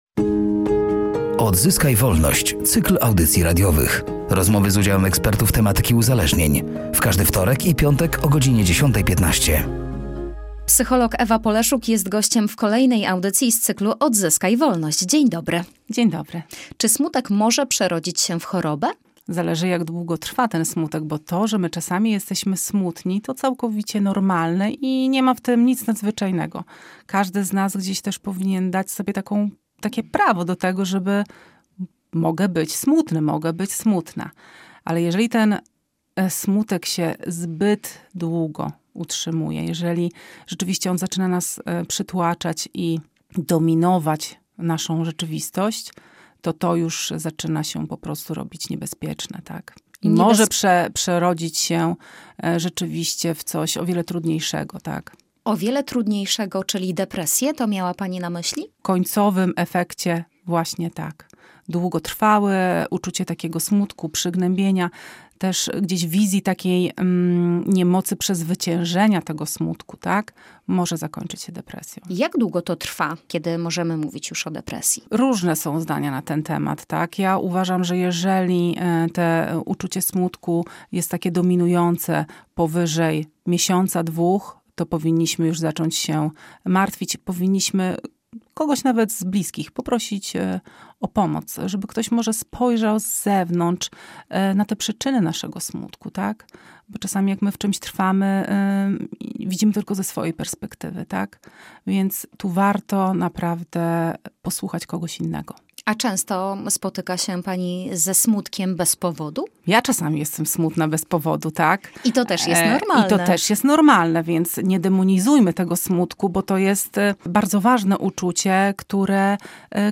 „Odzyskaj Wolność”, to cykl audycji radiowych poświęconych profilaktyce uzależnień wśród dzieci i młodzieży. W każdy wtorek i piątek o godzinie 10.15 na antenie Radia Nadzieja, eksperci dzielą się swoją wiedzą i doświadczeniem na temat uzależnień.